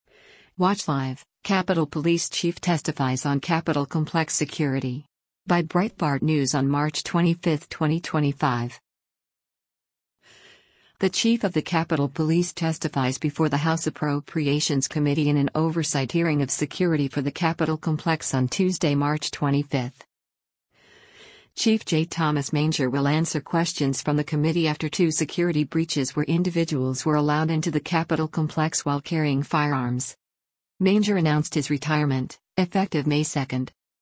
The chief of the Capitol Police testifies before the House Appropriations Committee in an oversight hearing of security for the Capitol Complex on Tuesday, March 25.